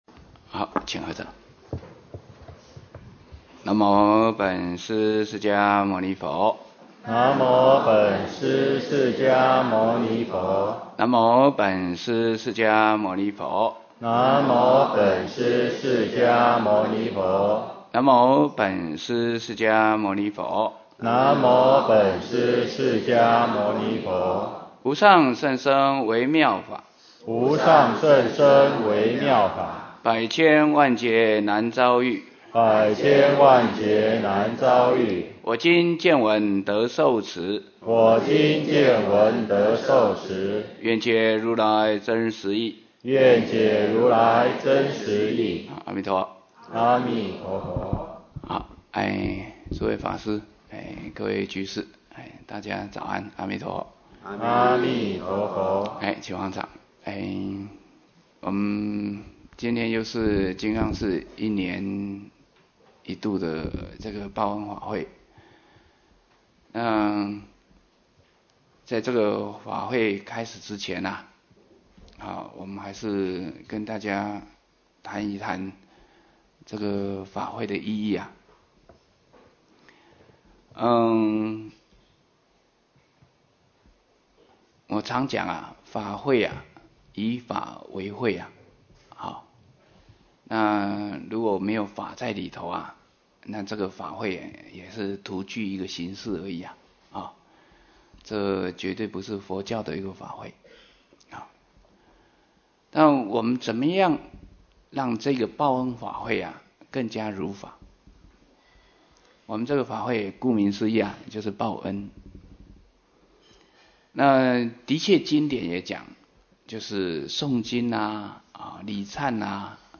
23报恩法会开示